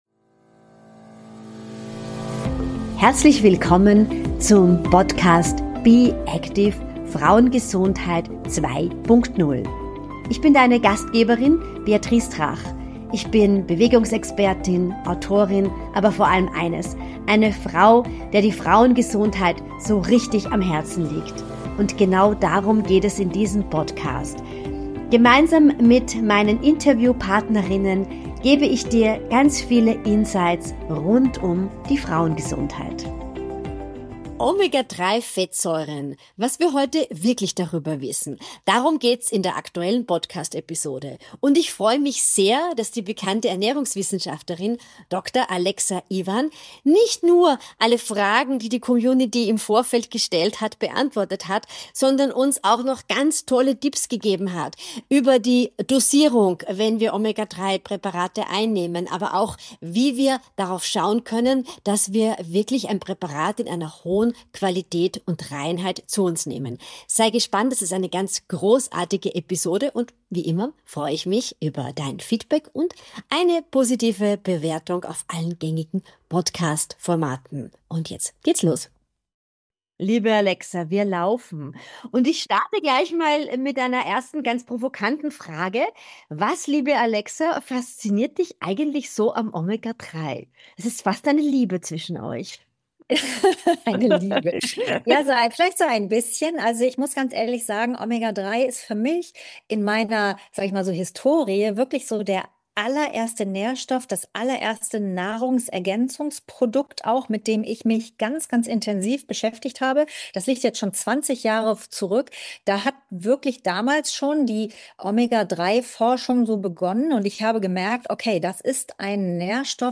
im Gespräch mit der Ernährungsexpertin